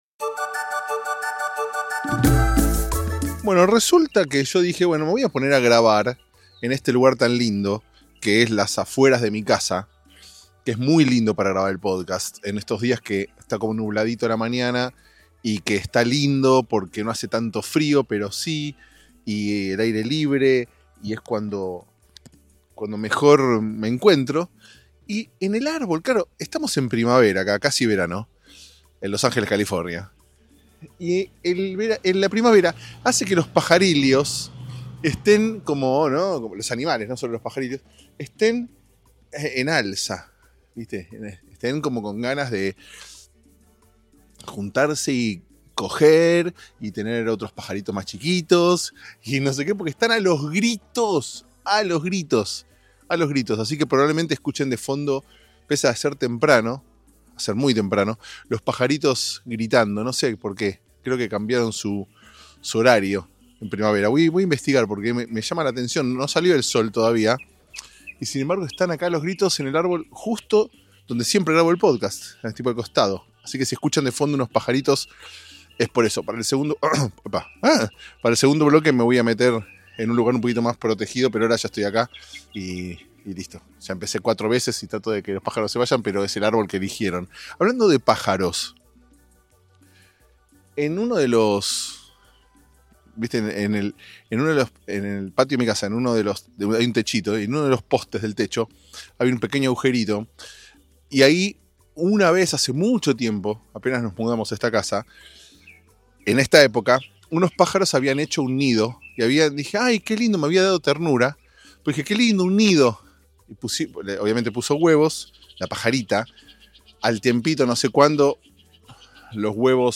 Dale que aunque los pájaros canten como loco, nosotros grabamos igual.